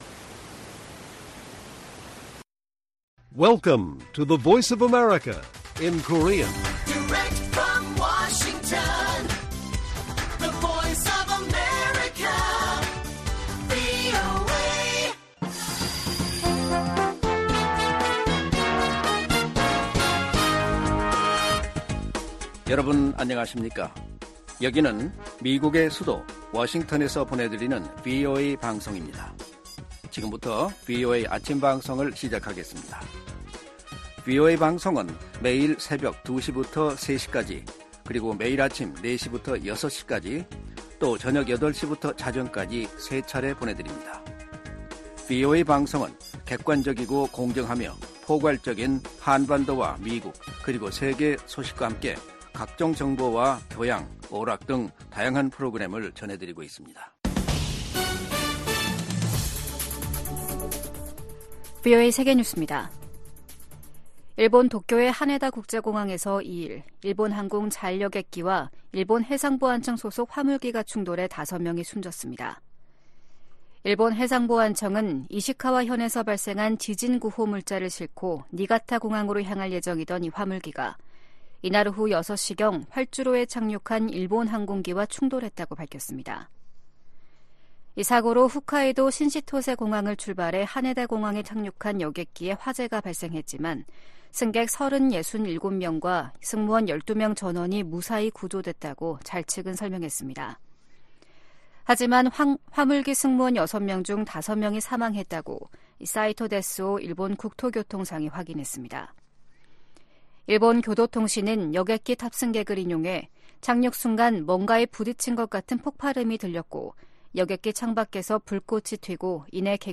세계 뉴스와 함께 미국의 모든 것을 소개하는 '생방송 여기는 워싱턴입니다', 2024년 1월 3일 아침 방송입니다. '지구촌 오늘'에서는 이스라엘 대법원이 베냐민 네타냐후 정부의 사법개혁안을 일부 무효화한 소식 전해드리고, '아메리카 나우'에서는 여러 경제 지표가 2024년 좋은 출발을 가리킨다고 백악관 경제자문위원장이 평가한 이야기 살펴보겠습니다.